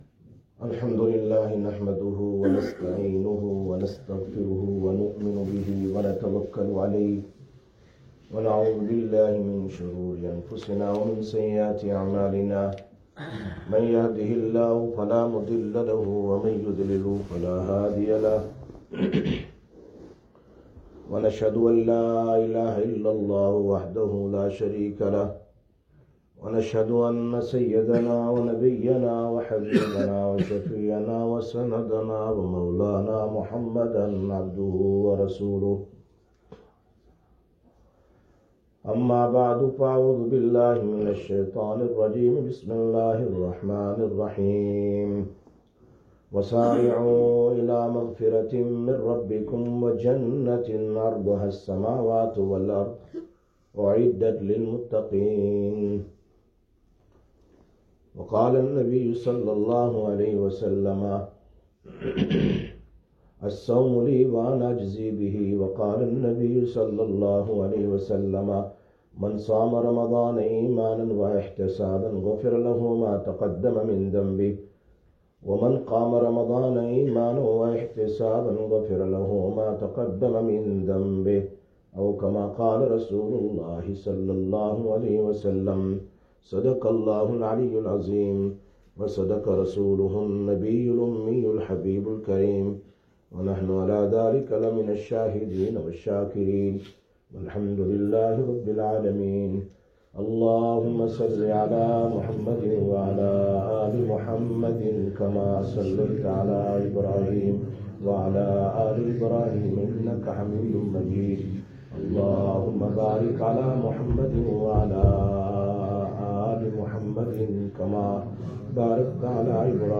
23/04/2021 Jumma Bayan, Masjid Quba